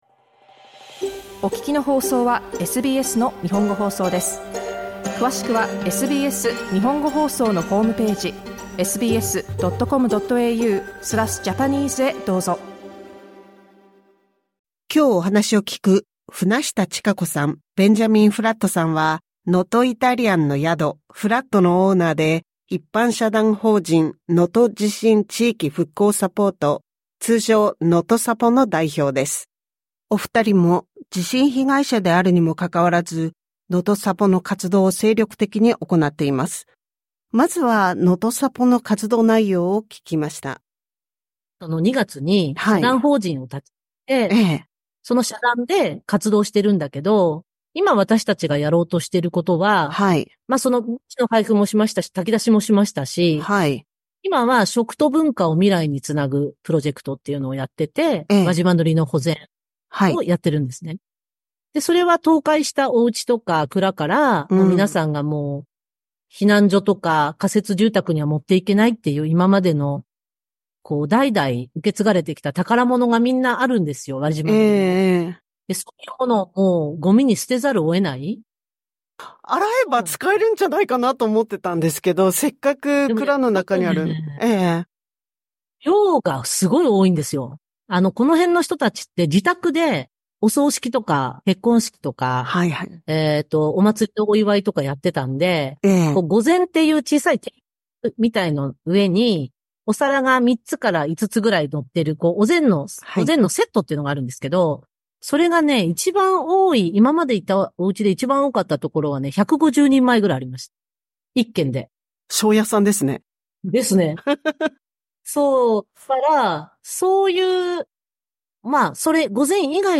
詳しくはインタビューをオーディオで聞いてください。